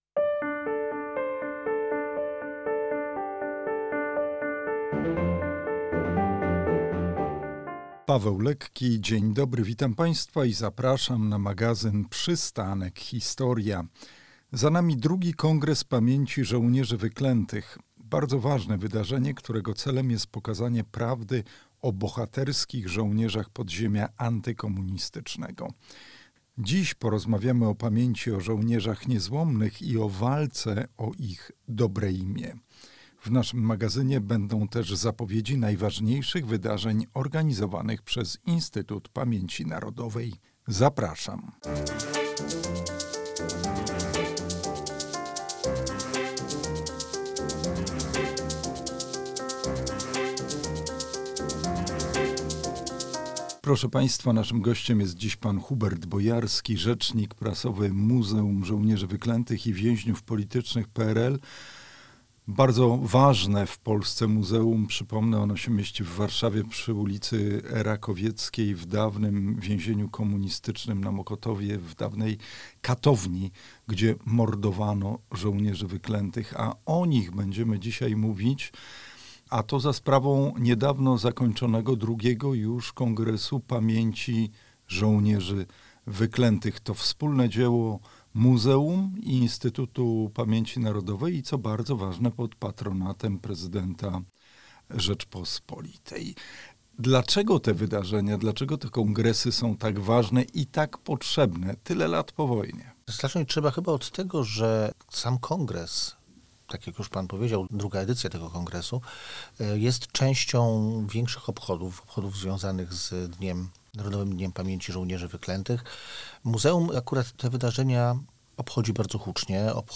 W „Przystanku Historia” znajdą Państwo także zapowiedzi i relacje najciekawszych wydarzeń organizowanych przez IPN.